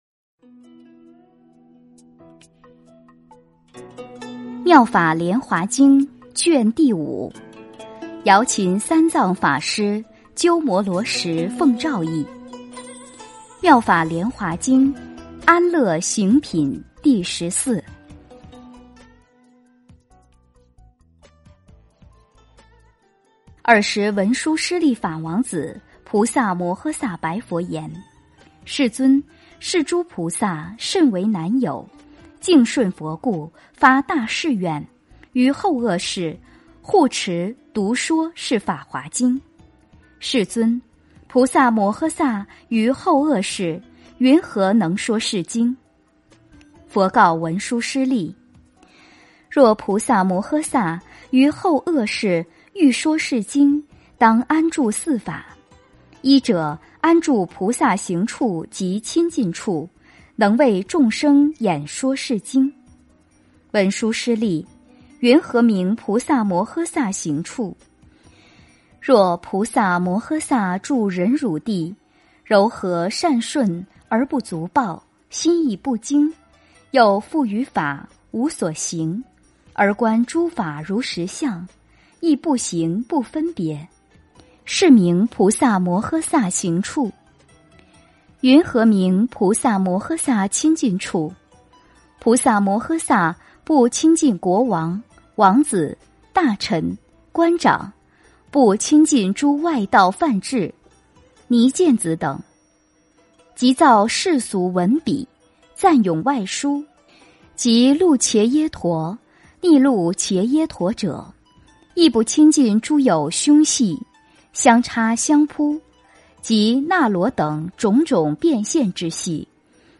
《妙法莲华经》安乐行品第十四 - 诵经 - 云佛论坛